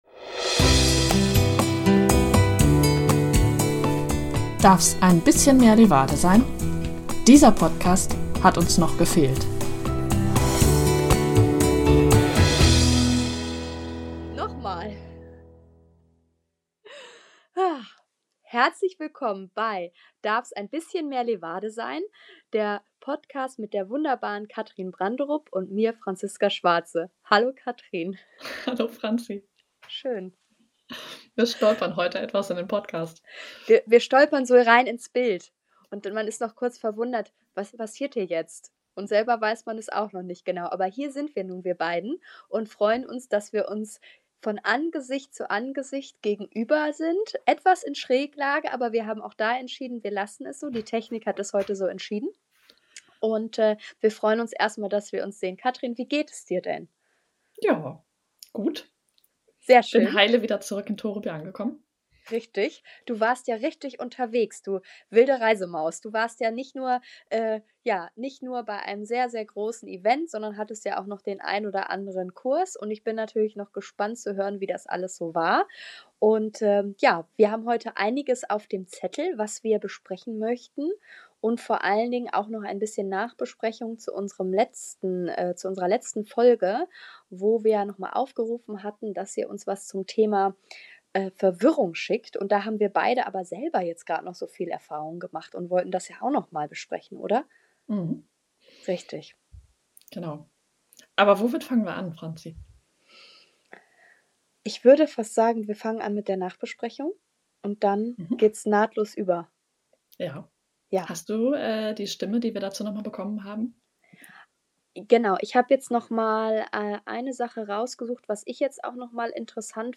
In dieser Folge sprechen unsere beiden Podcasterinnen über Resilienz bei Pferden und Menschen.